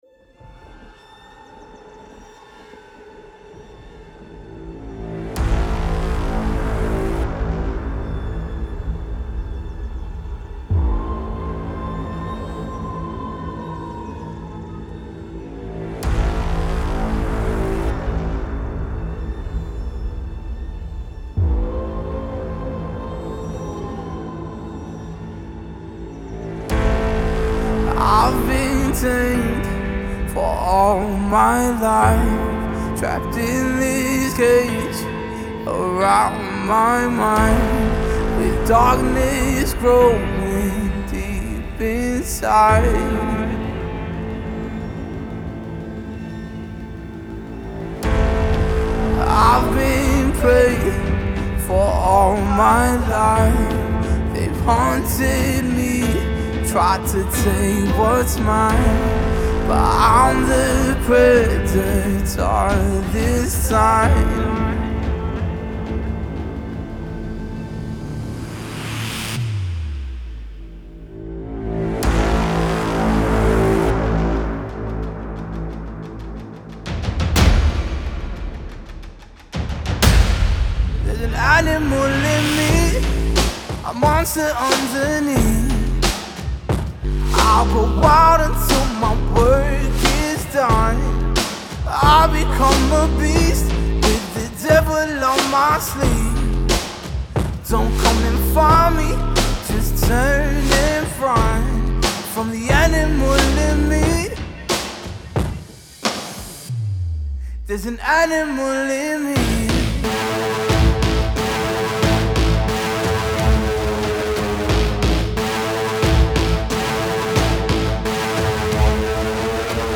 Genre: Vocals & Songs.